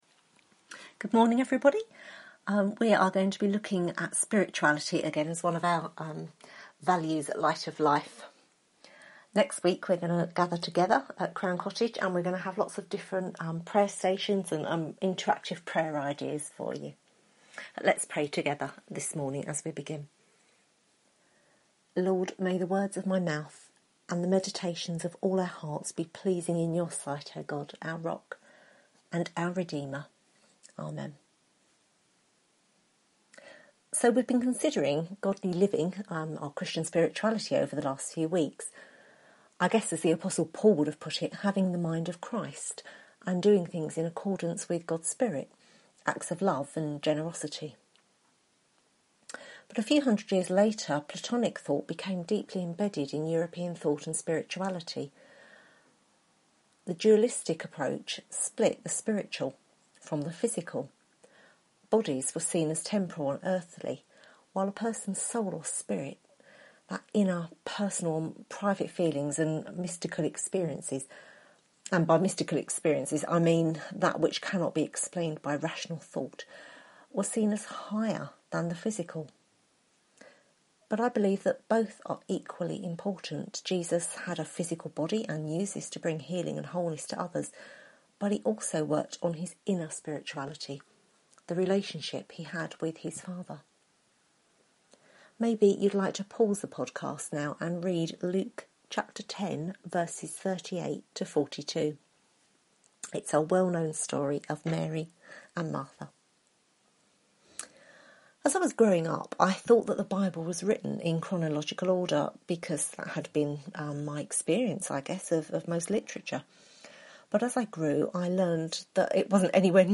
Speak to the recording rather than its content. Series: Christian Spirituality Service Type: Sunday Morning